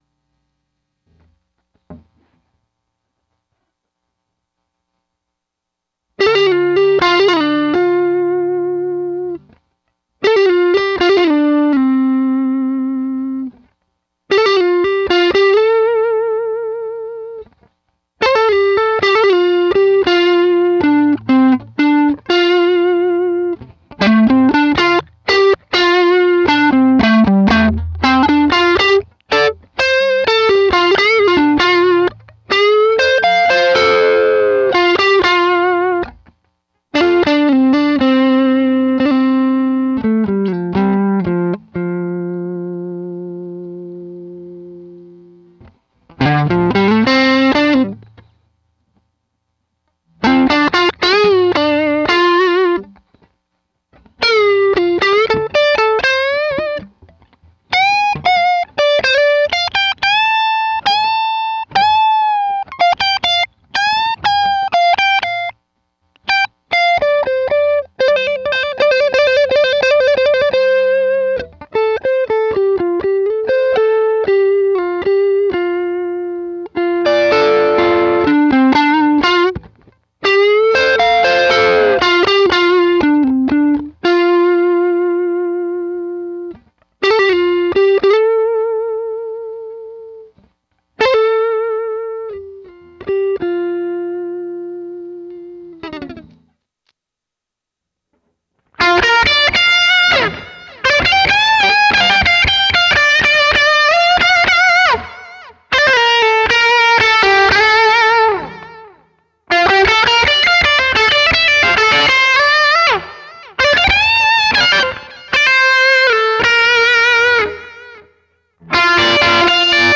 120 BPM
Just riffin on my new tele. 120 bpm